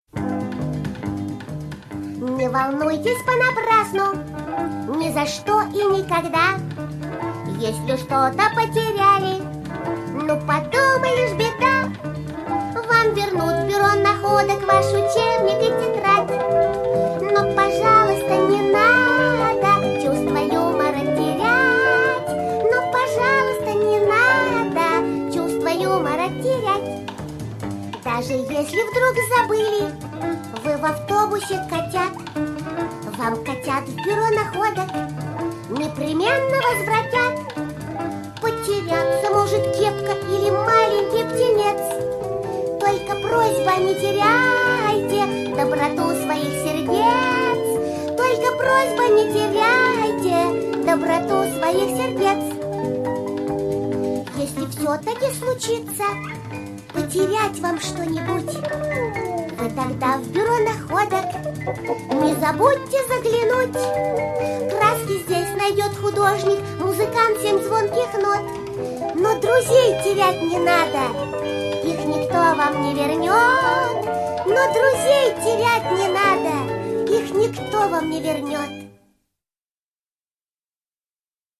исполненная тоненьким голоском
Детская песенка